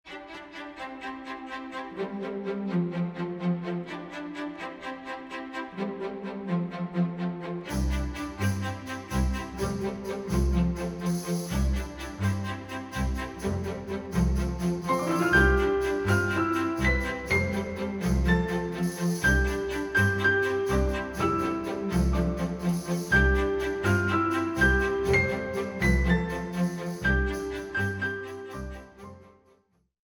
彼らの目指すクラッシクスタイルの新しい解釈・アレンジ・演奏により、新たなる魅力が吹き込まれる。
シドニーフォックススタジオEQで録音され